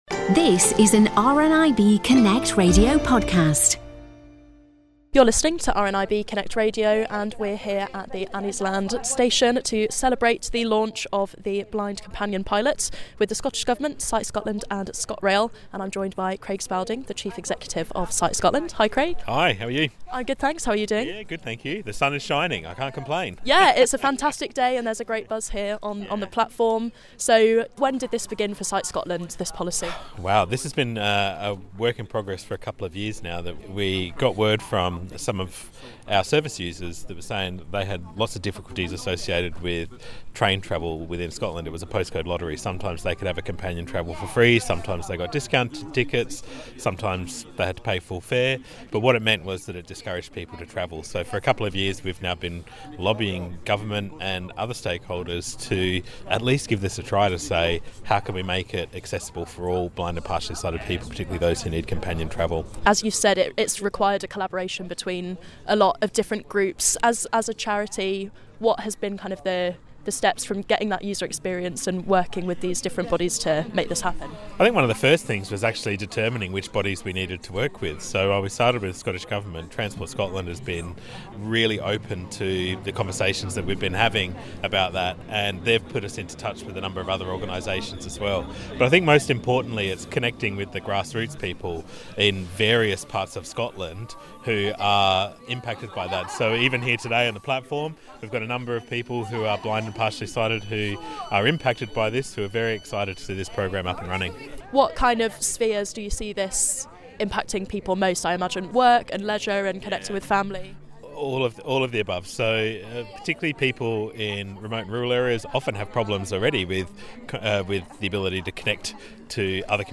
went along to the launch at Anniesland Station in Glasgow